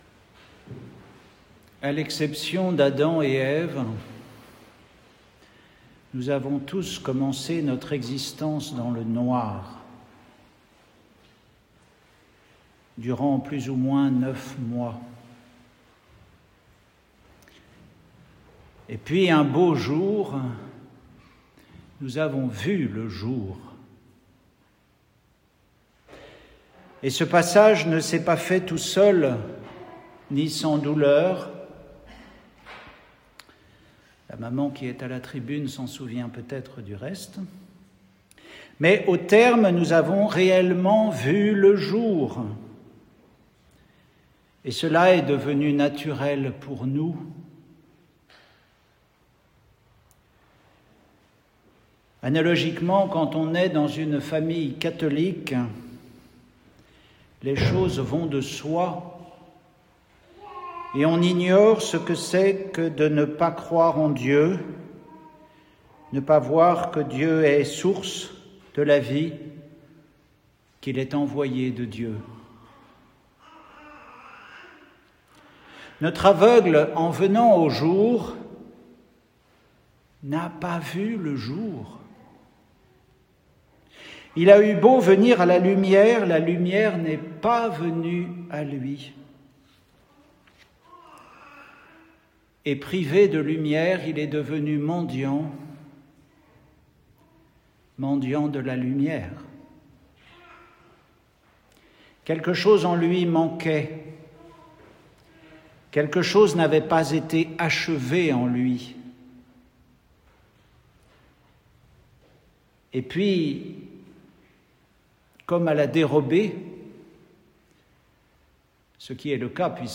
Retrouvez les méditations d’un moine sur les lectures de la messe du jour.